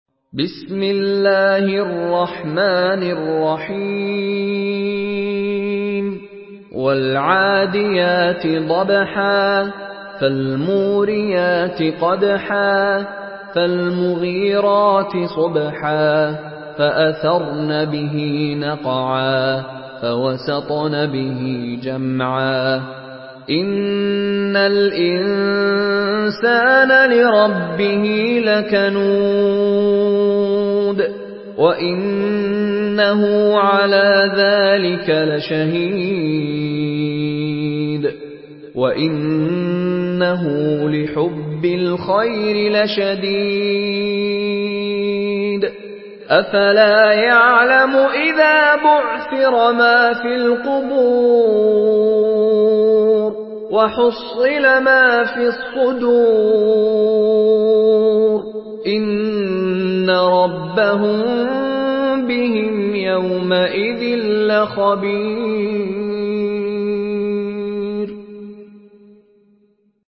Surah Al-Adiyat MP3 in the Voice of Mishary Rashid Alafasy in Hafs Narration
Surah Al-Adiyat MP3 by Mishary Rashid Alafasy in Hafs An Asim narration.
Murattal Hafs An Asim